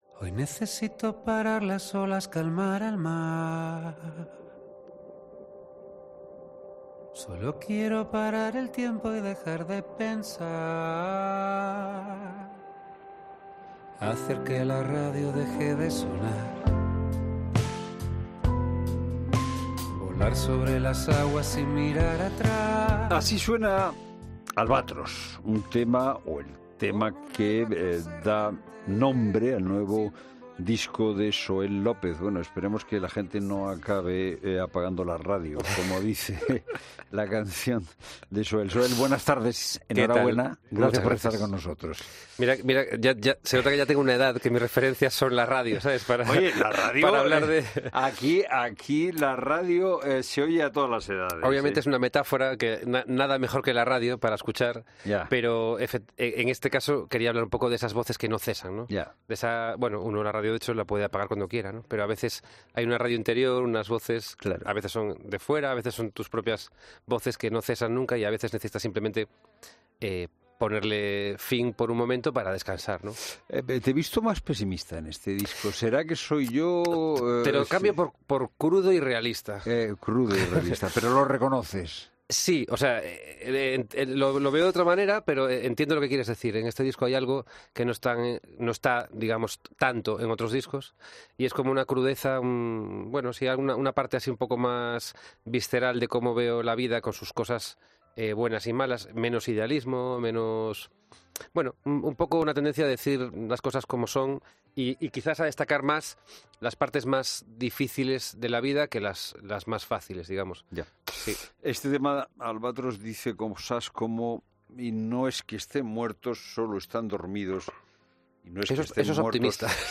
AUDIO: El cantautor ha venido hasta 'La Tarde' para charlar junto a Fernando de Haro de su nueva música y del significado de sus canciones y poemas